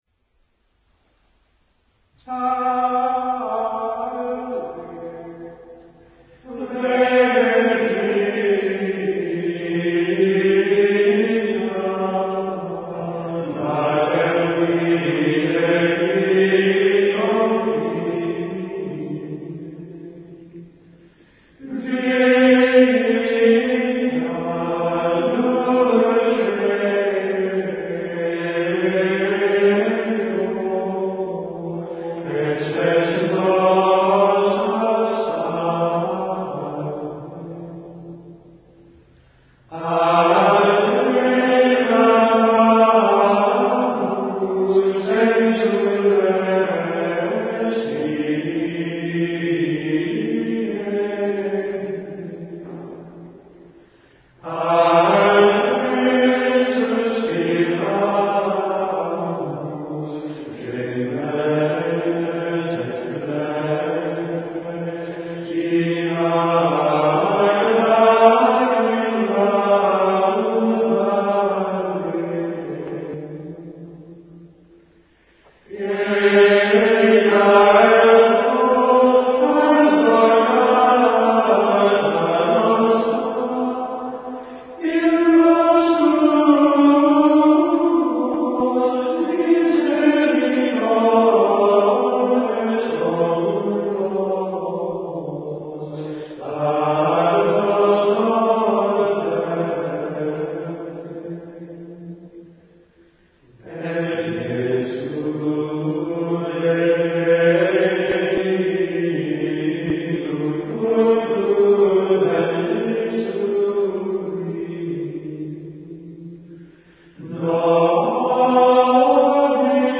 GREGORIANA
brano di musica Gregoriana(320K).
La musica gregoriana ha permeato l'intero medioevo di suoni mistici.